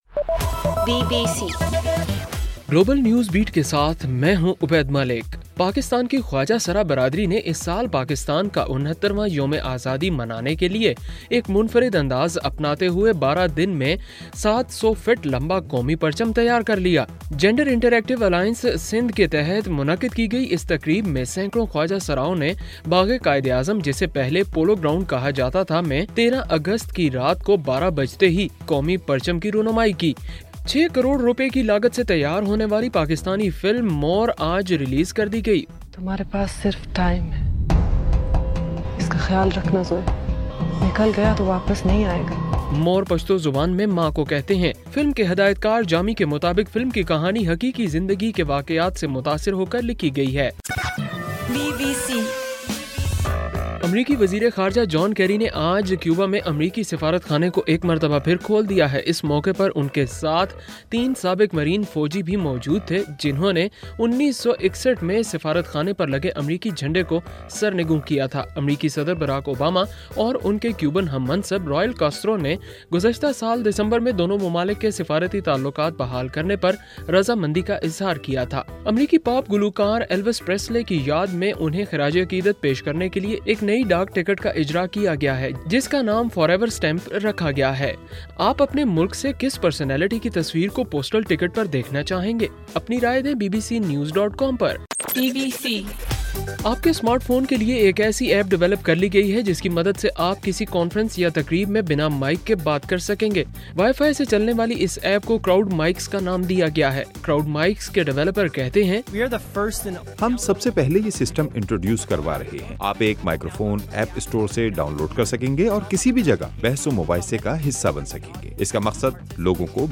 اگست 14: رات 11 بجے کا گلوبل نیوز بیٹ بُلیٹن